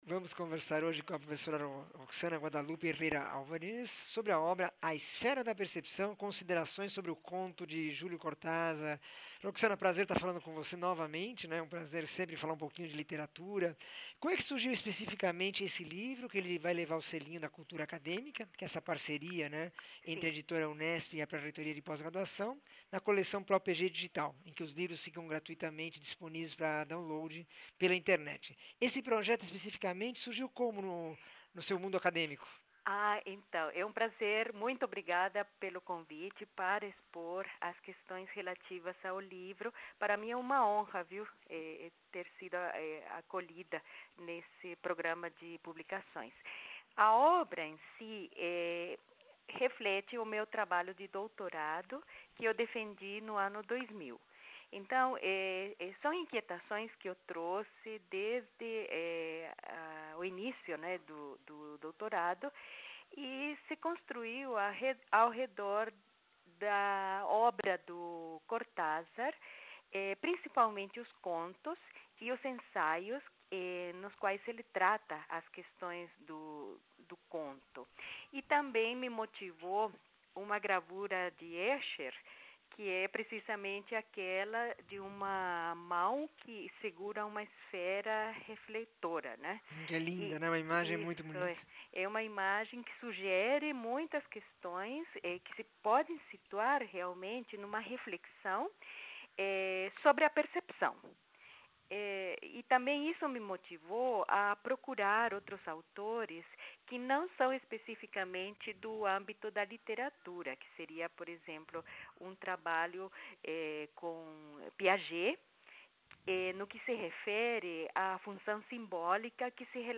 entrevista 1711
Entrevista